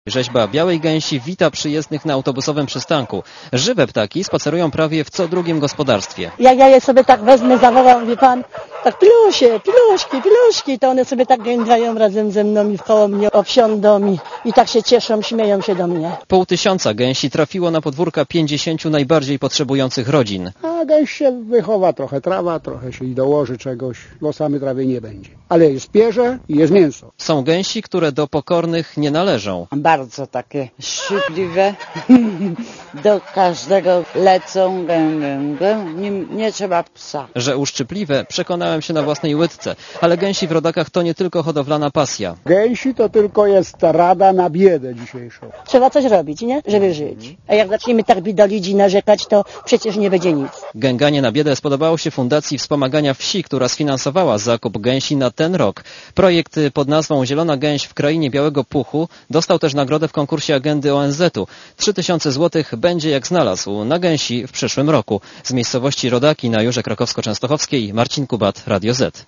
Posłuchajcie relacji reportera Radia Zet z Radaków.